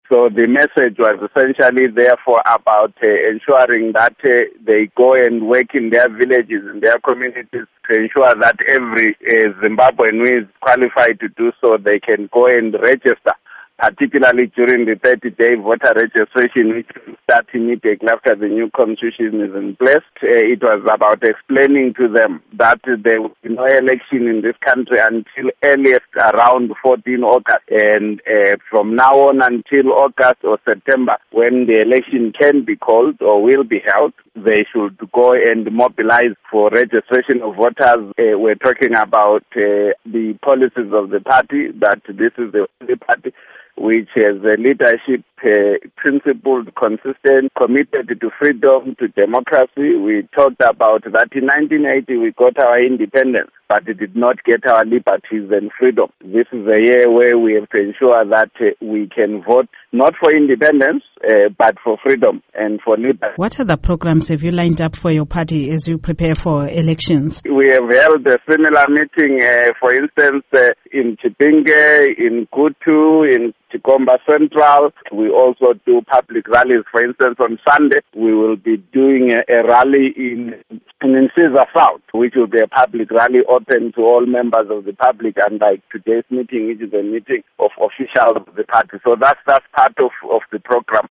Interview with Welshman Ncube